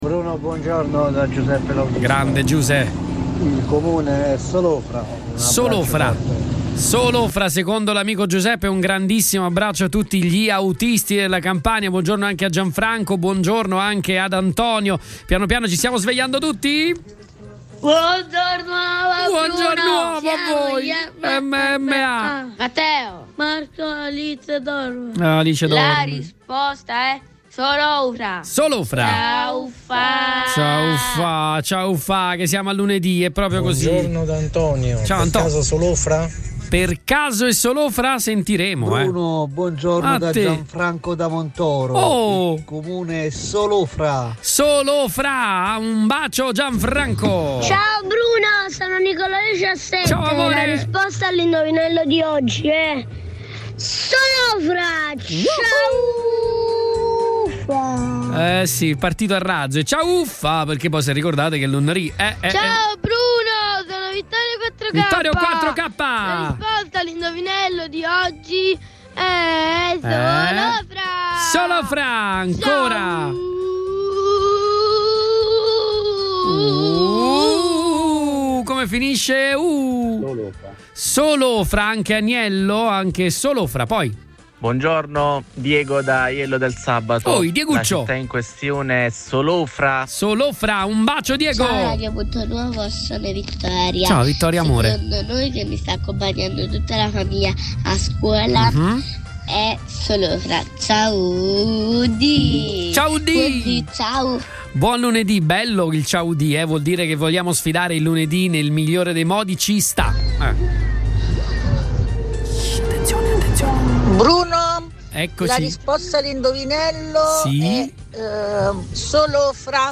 AUDIO  – RIASCOLTA I WHATSAPP DELLA DIRETTA